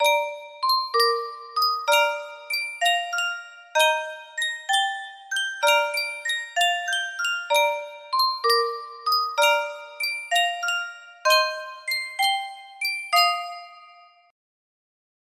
Sankyo Music Box - Meet Me in St. Louis D- music box melody
Full range 60